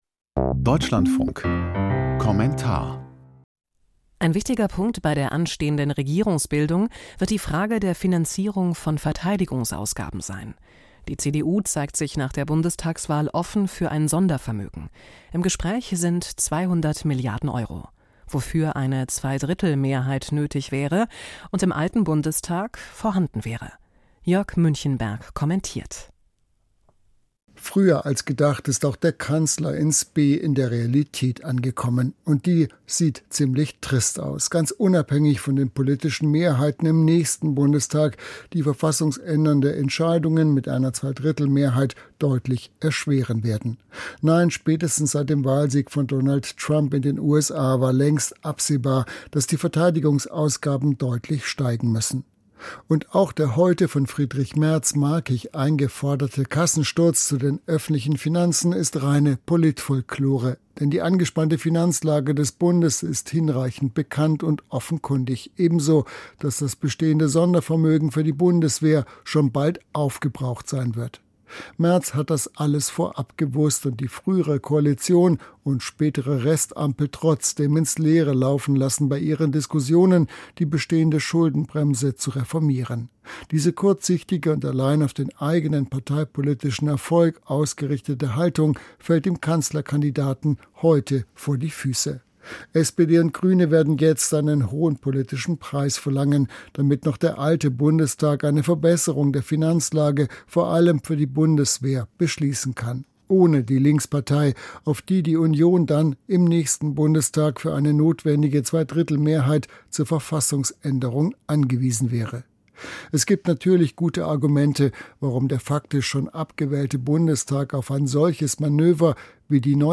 Kommentar: Ein parteiübergreifender Kompromiss ist gefragt